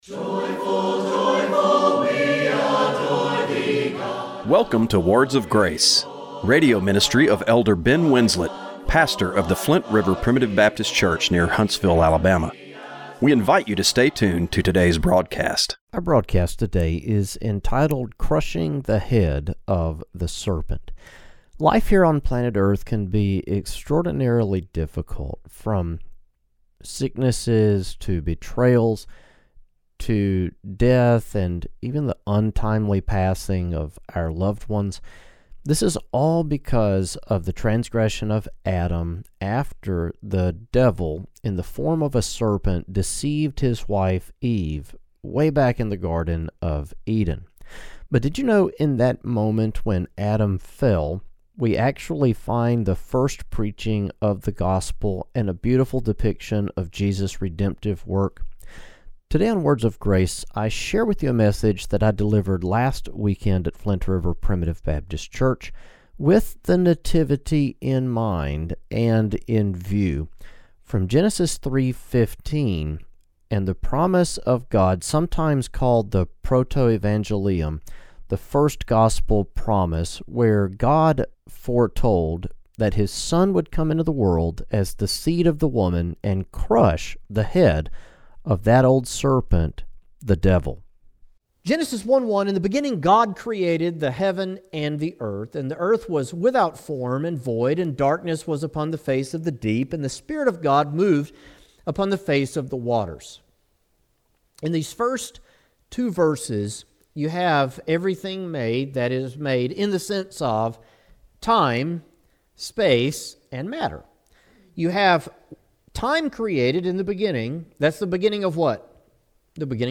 Radio broadcast for December 15, 2025.